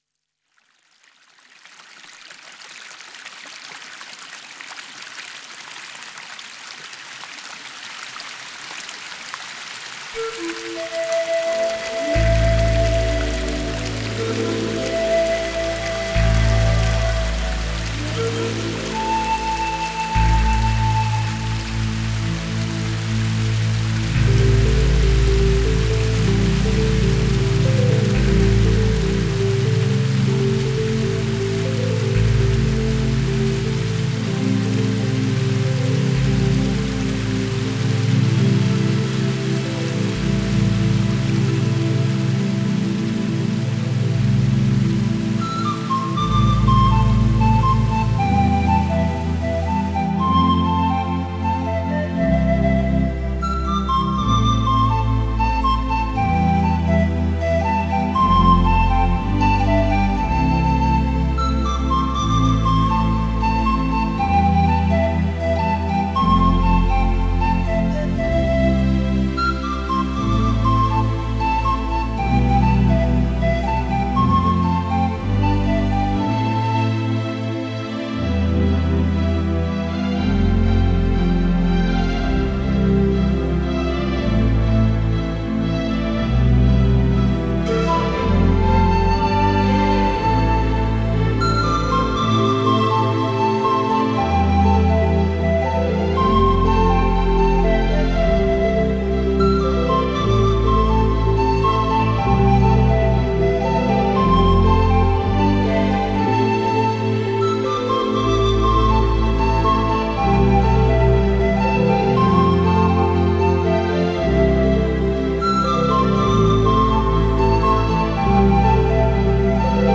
Nature : musique relaxante que je passe aux élèves au début d'une évaluation.